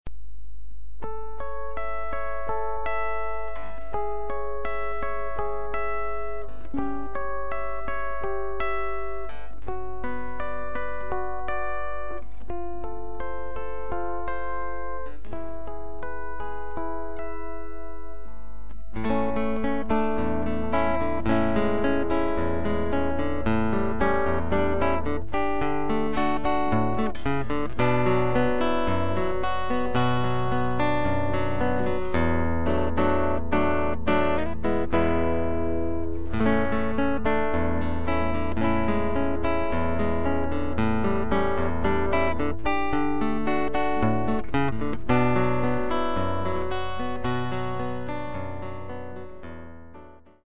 A great moment of  Picking style music.